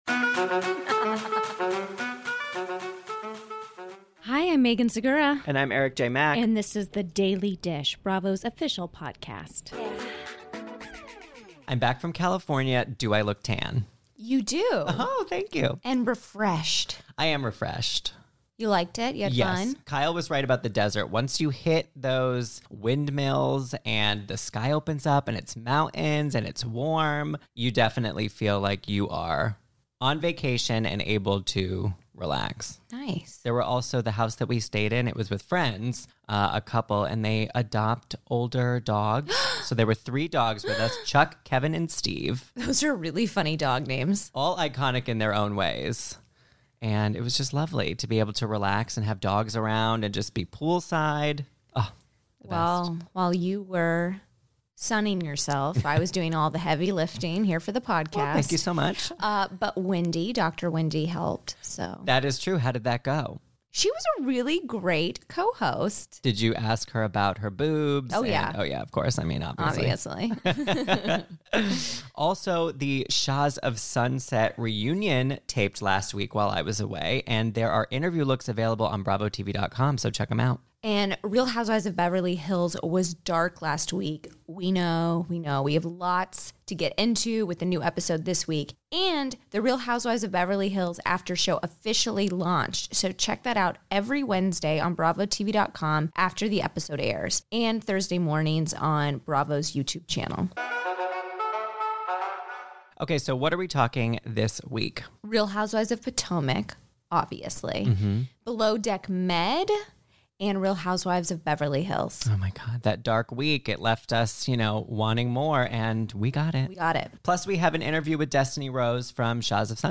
Plus, Shahs of Sunset’s Destiney Rose calls in to share where she stands with Paulina Ben Cohen today, if she and Nema Vand have hooked up, and what ...